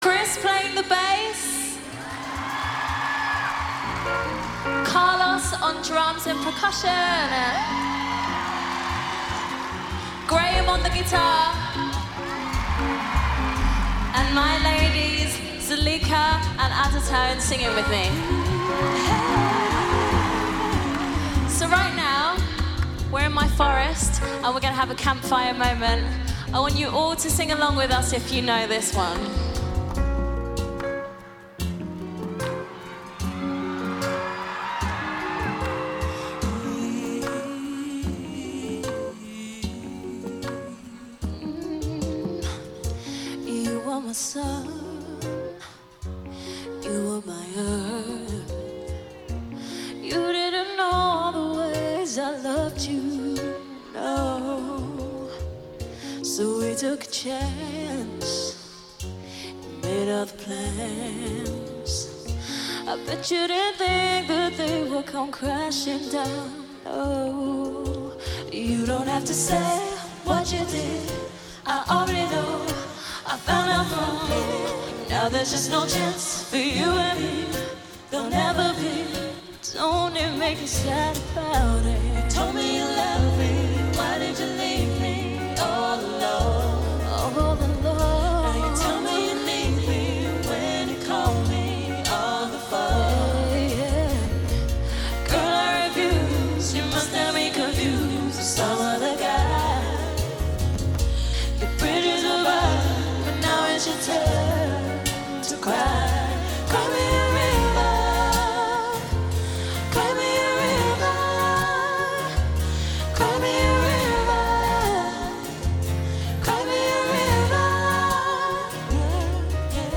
Synth-pop